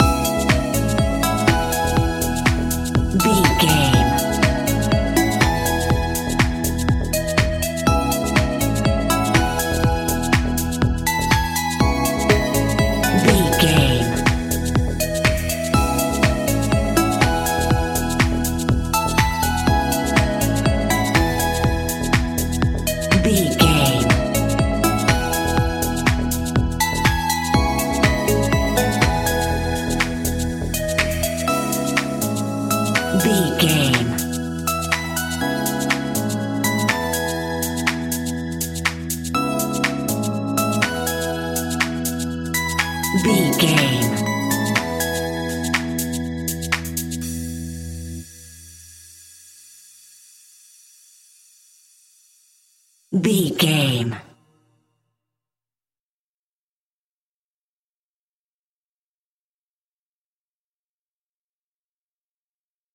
Ionian/Major
groovy
uplifting
energetic
synthesiser
electric piano
drum machine
house
electro house
synth leads
synth bass